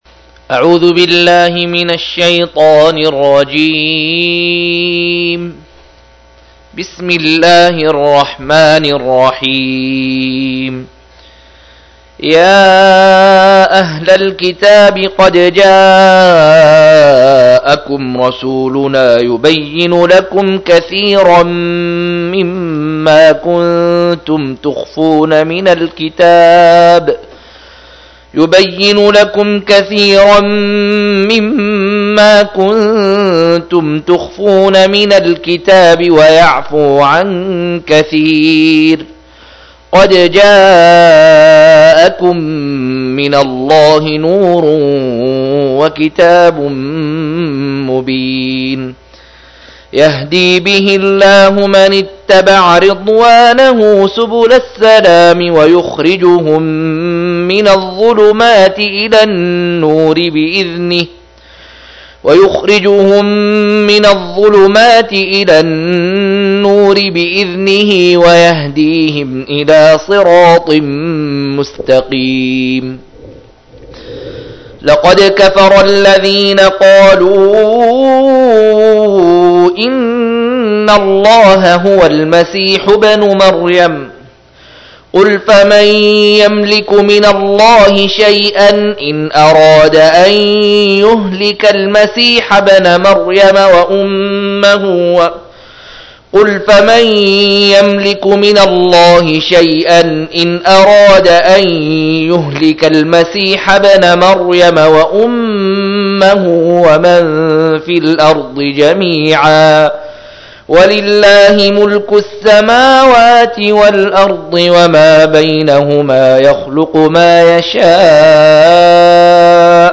112- عمدة التفسير عن الحافظ ابن كثير رحمه الله للعلامة أحمد شاكر رحمه الله – قراءة وتعليق –